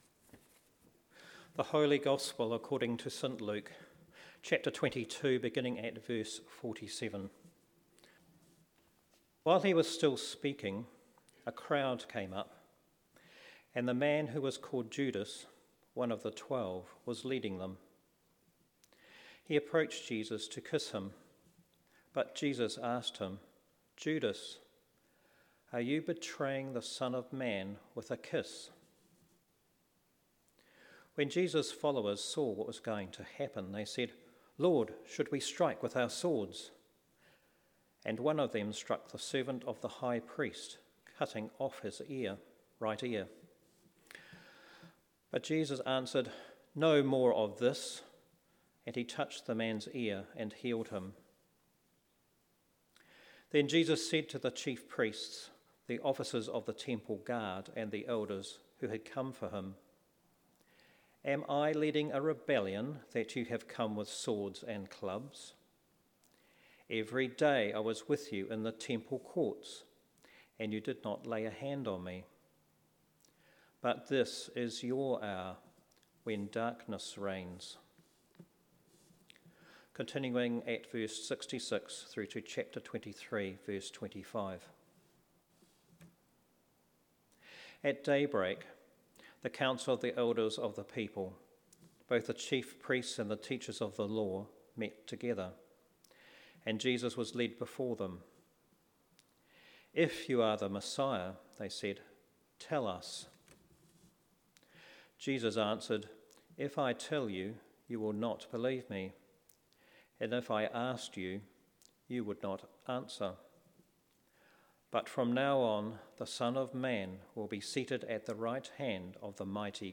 A very theological sermon based in part on Karl Barth, Church Dogmatics IV,1.